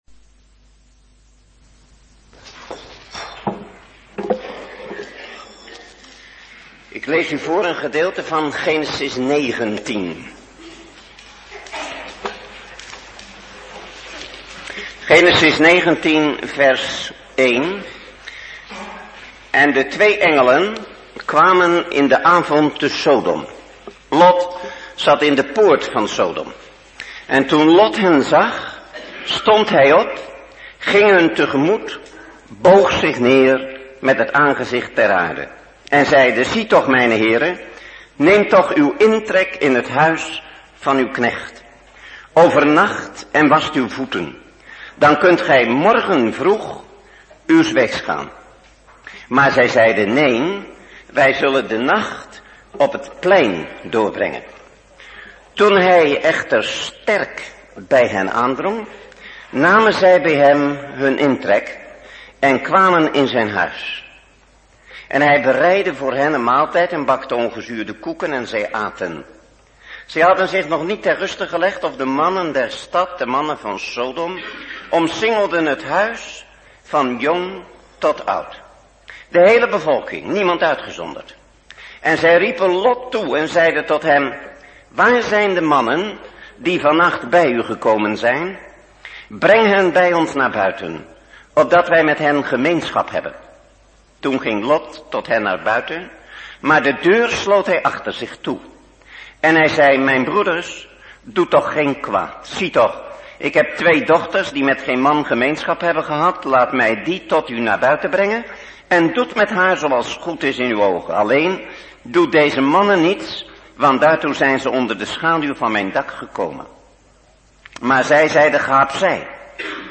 Een preek over 'God redt!'.